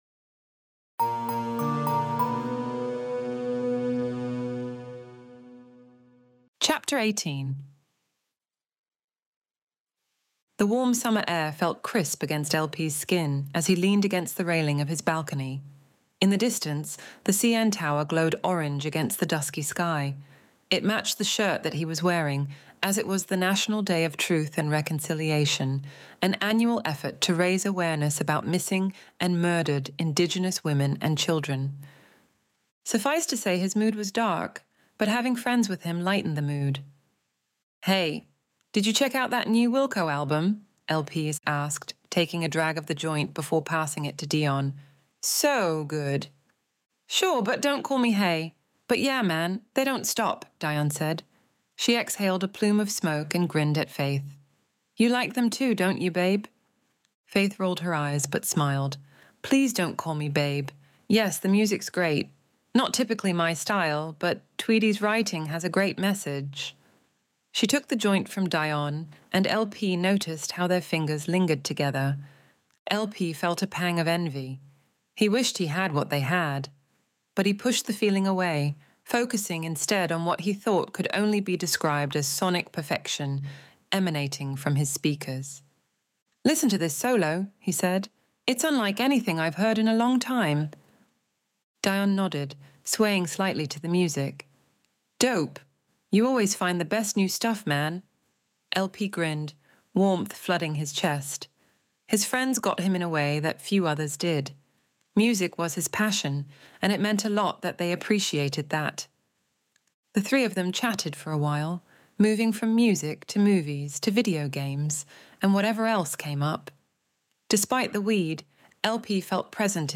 Extinction Event Audiobook Chapter 18